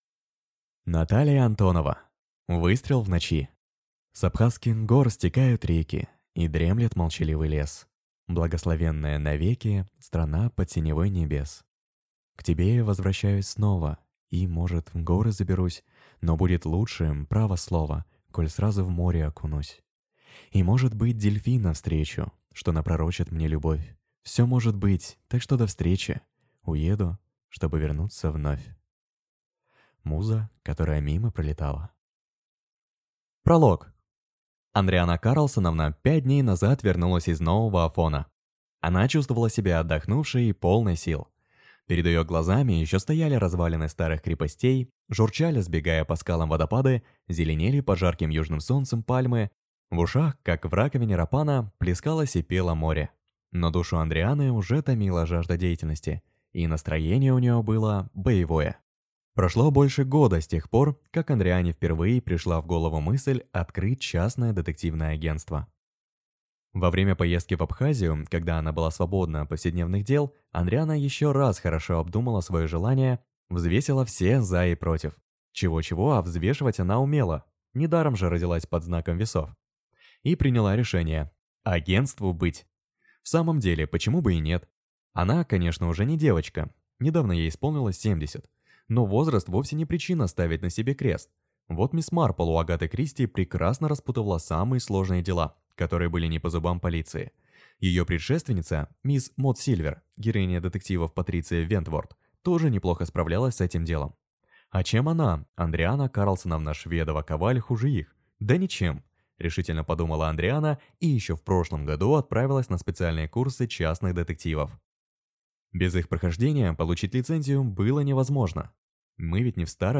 Аудиокнига Выстрел в ночи | Библиотека аудиокниг